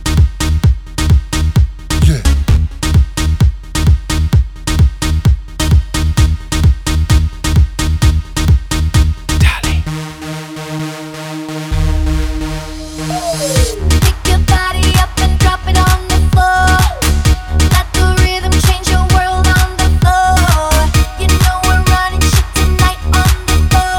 No Rapper Pop (2010s) 3:49 Buy £1.50